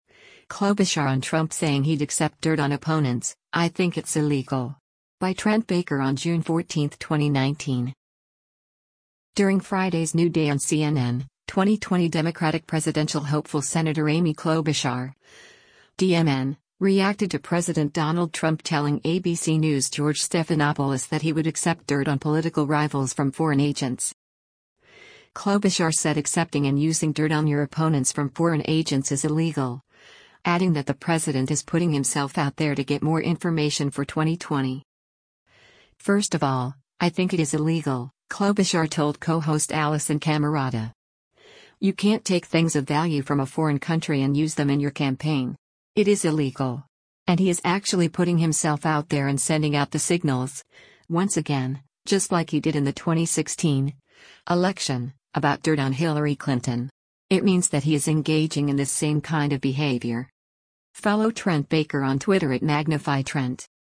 During Friday’s “New Day” on CNN, 2020 Democratic presidential hopeful Sen. Amy Klobuchar (D-MN) reacted to President Donald Trump telling ABC News’ George Stephanopoulos that he would accept dirt on political rivals from foreign agents.